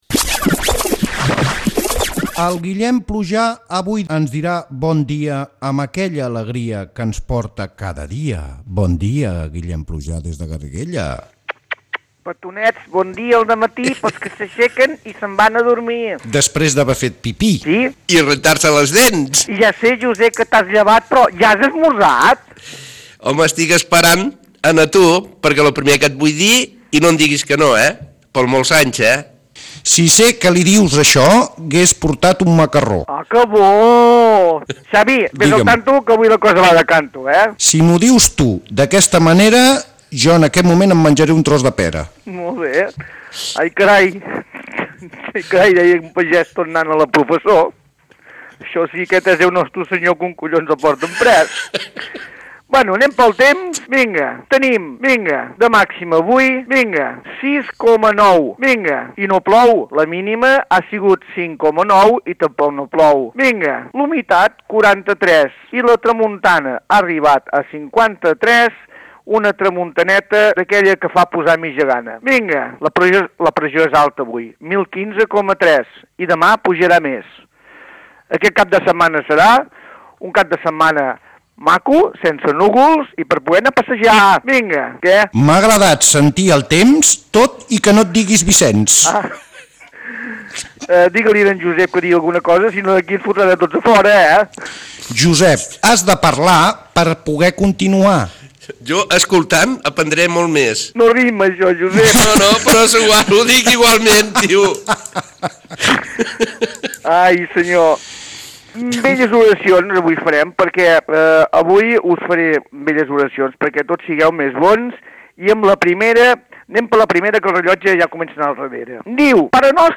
Espai del temps: dades meteorològiques i previsió per al cap de setmana. Oracions rimades i diàleg improvisat rimat.
Entreteniment